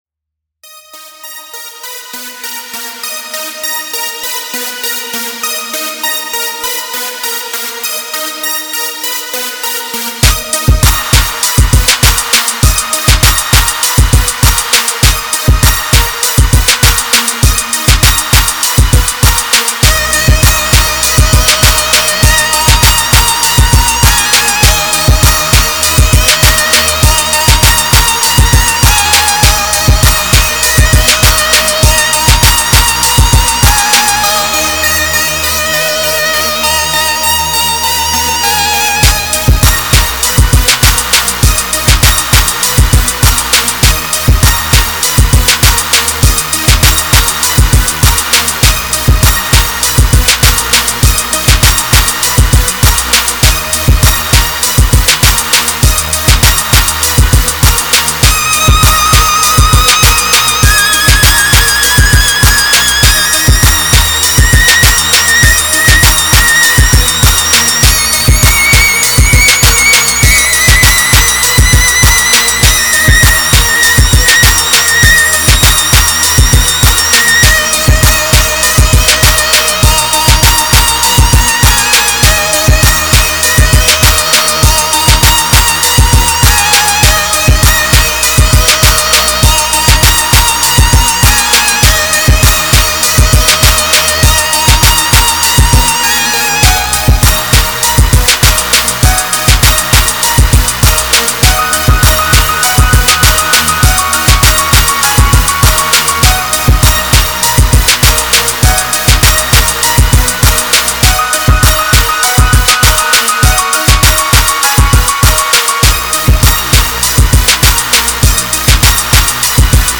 Club Track 100 BPM